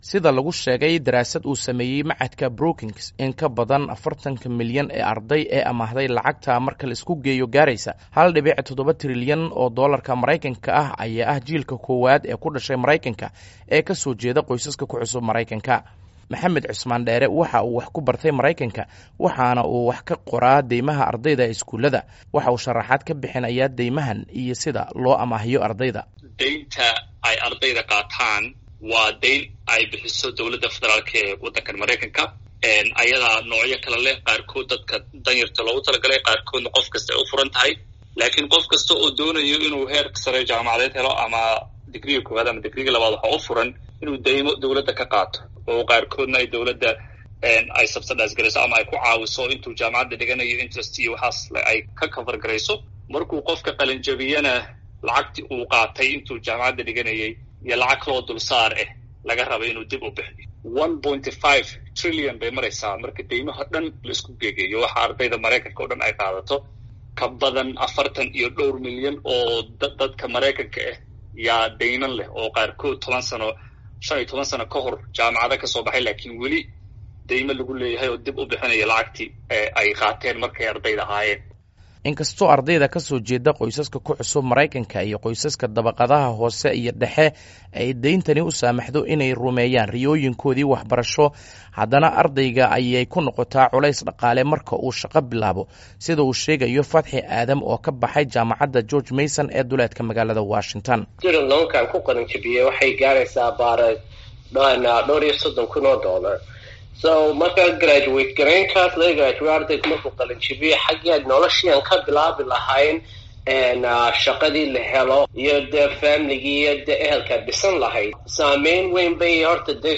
warbixin ka diyaariyay